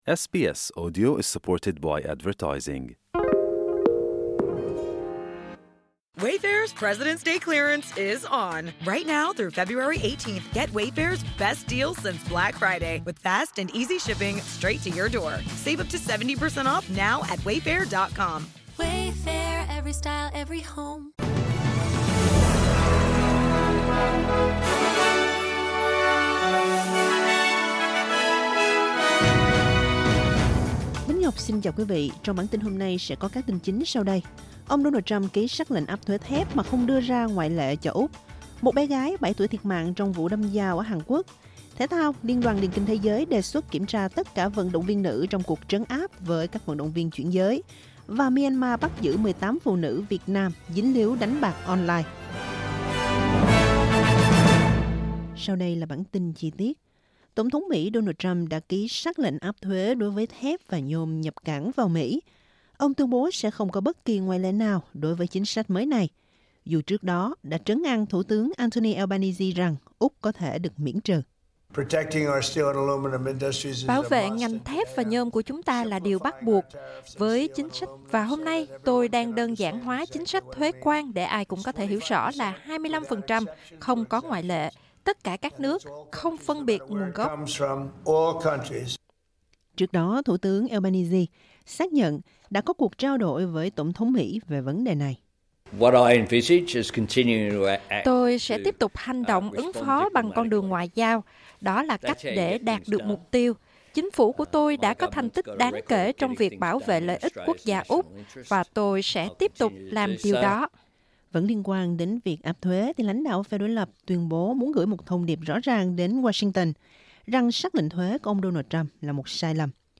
Vietnamese news bulletin Source: AAP